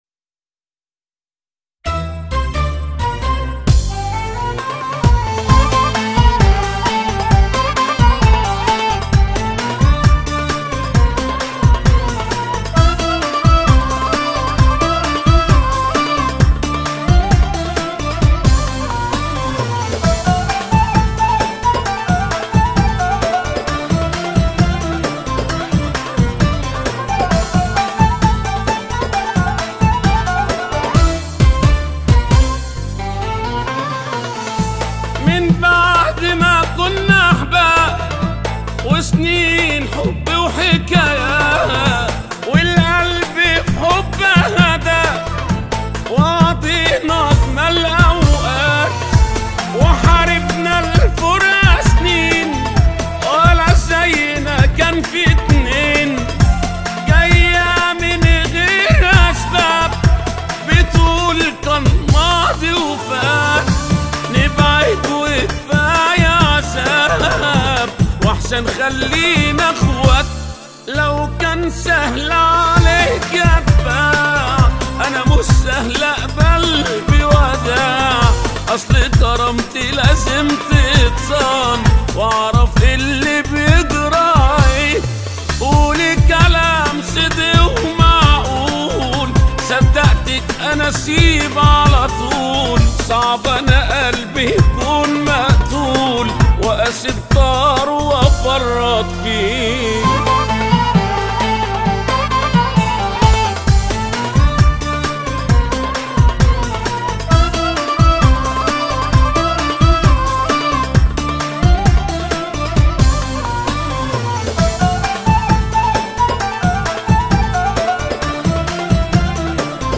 اغانى شعبي